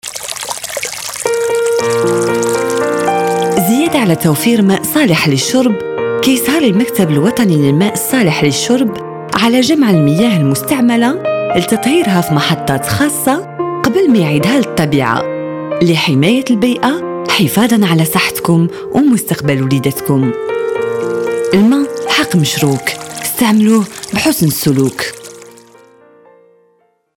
-2 Spot Radio « Assainissement»